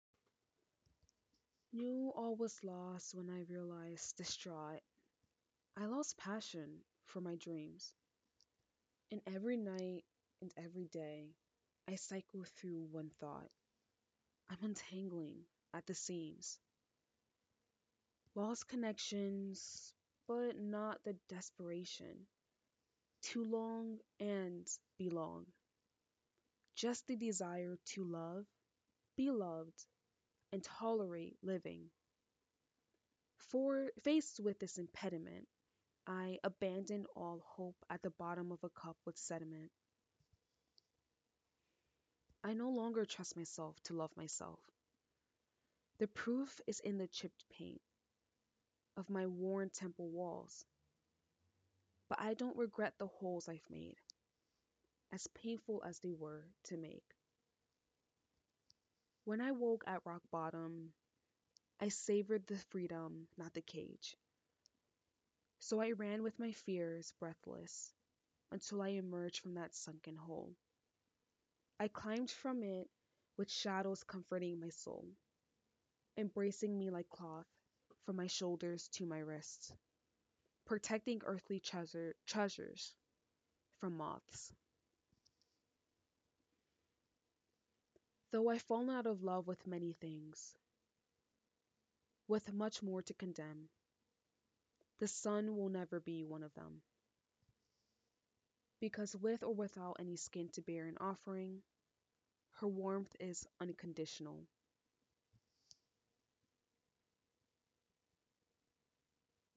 spoken word (demo)